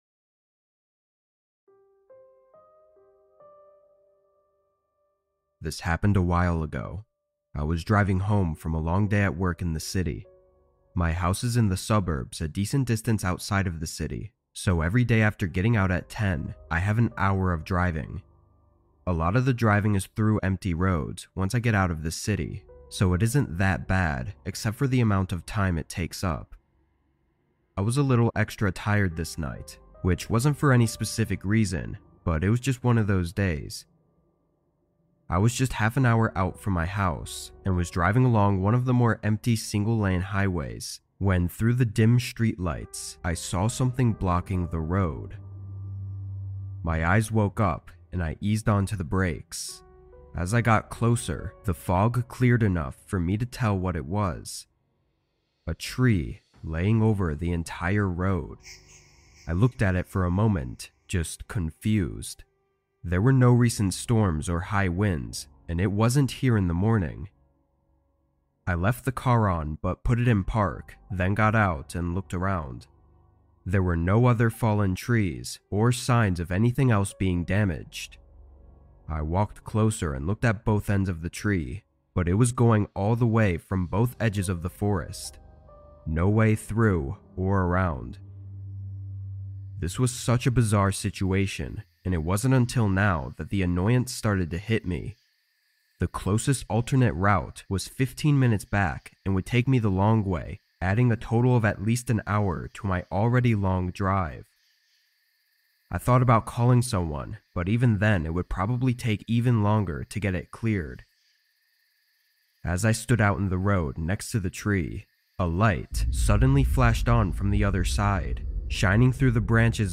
3 True Rainy Night Horror Stories | Vol. 2